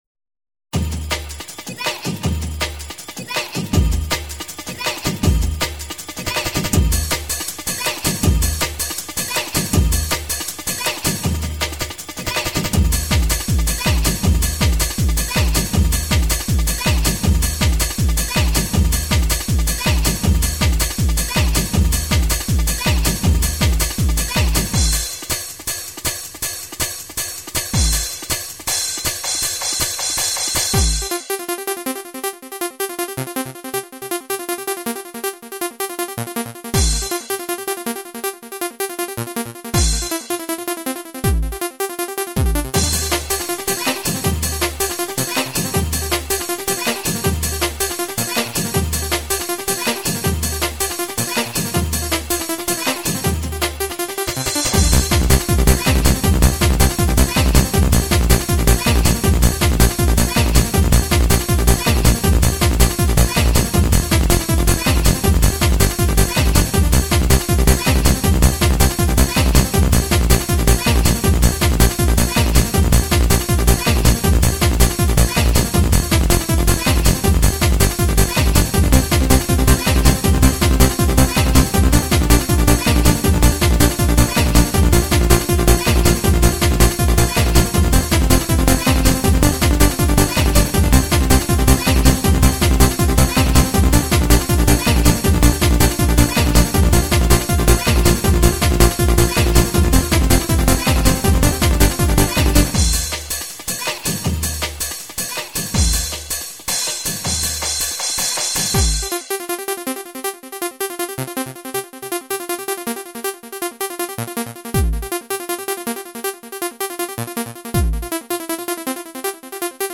[remix]
• Jakość: 44kHz, Stereo